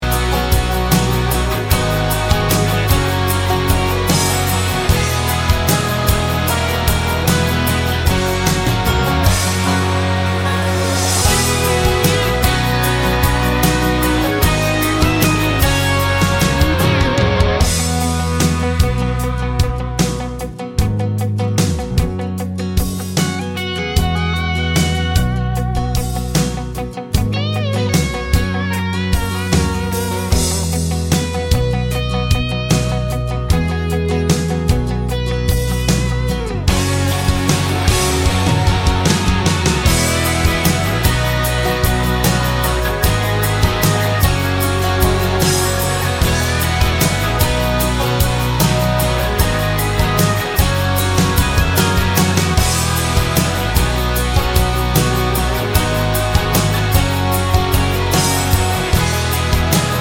no Backing Vocals Country (Male) 3:01 Buy £1.50